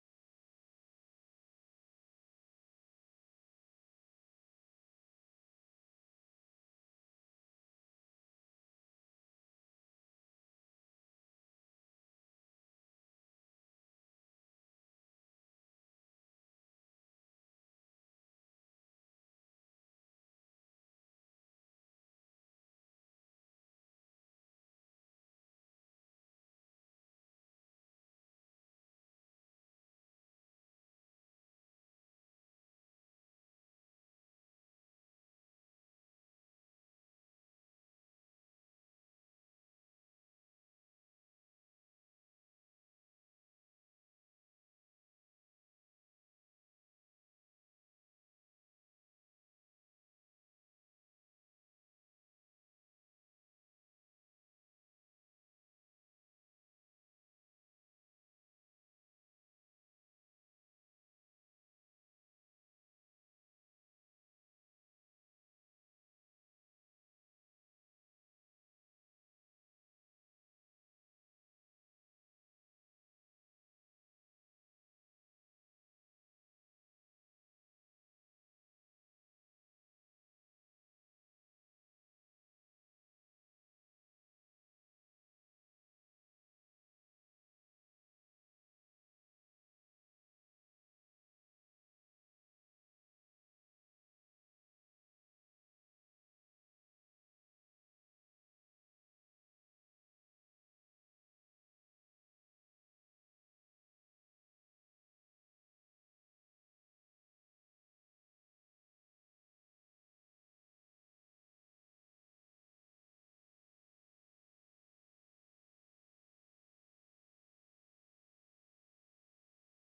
Being a Christian – The Royal Law Sermon